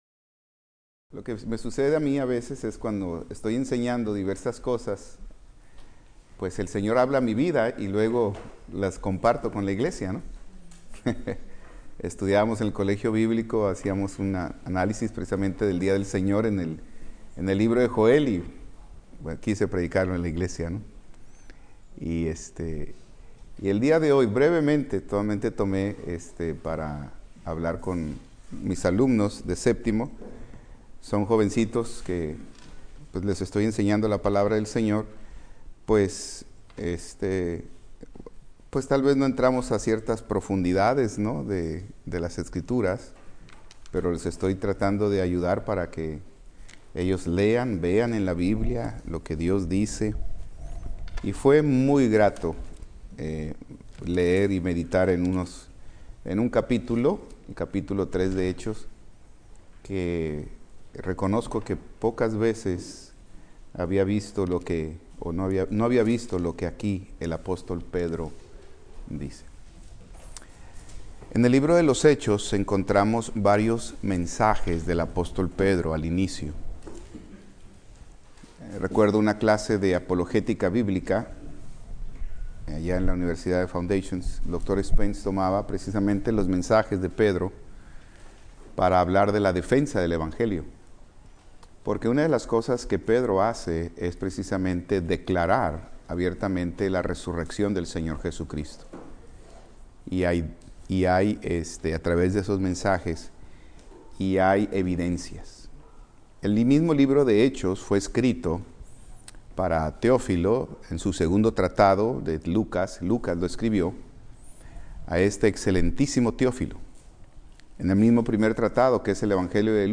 Servicio Miércoles